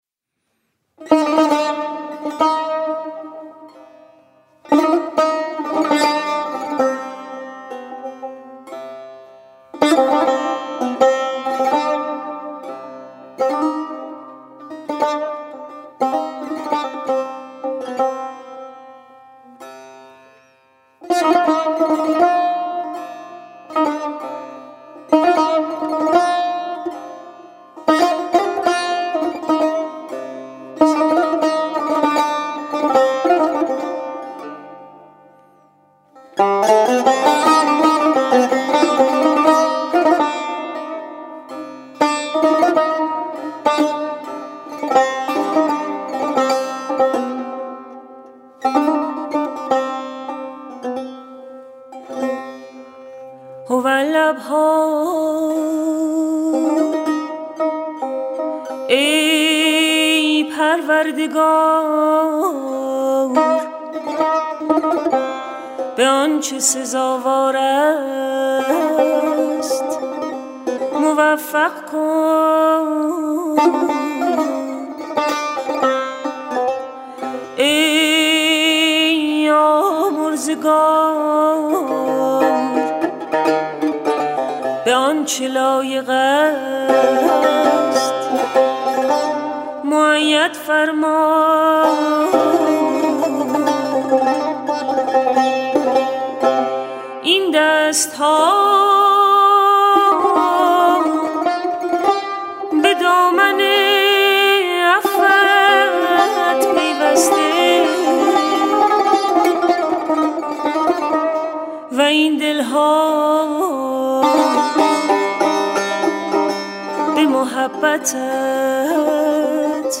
مجموعه مناجات های فارسی همراه با موسیقی
in sedaie ro'iaii az kist bi nahayat rohani va delneshin moteshakeram